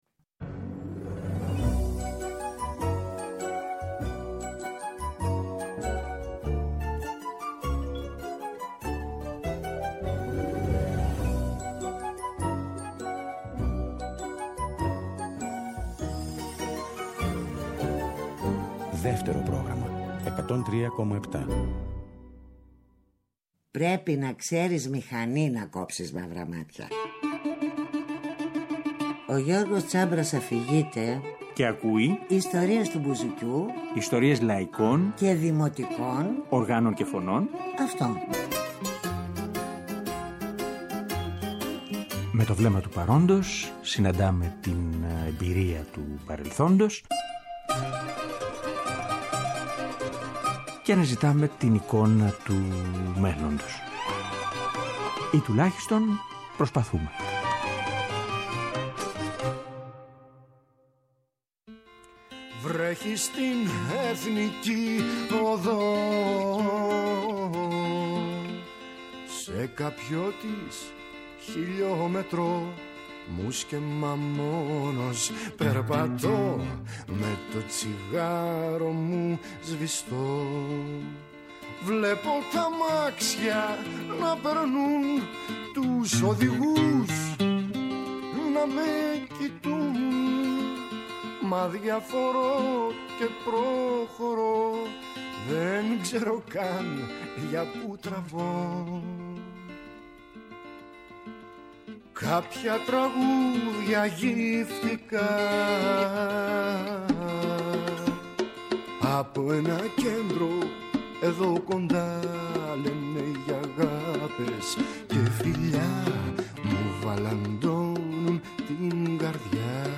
Κάποια αποσπάσματα αυτής της συζήτησης θα διαβάσουμε στη σημερινή εκπομπή, διαλέγοντας ανάλογες «λεπτομέρειες» από την όλη τη δισκογραφία του.